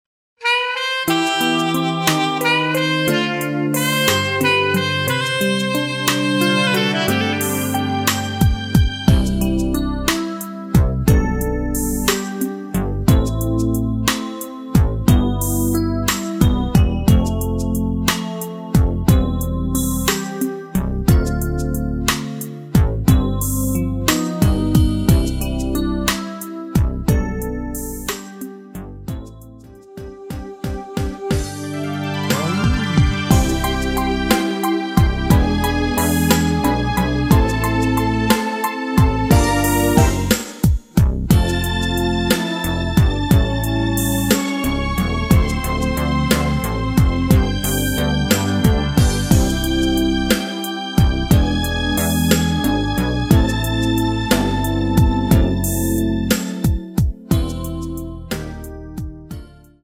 MR입니다. 전주가 길어서 앞에 코러스 부분은 노래 부르기 편하게 제작하지 않았습니다.
원곡의 보컬 목소리를 MR에 약하게 넣어서 제작한 MR이며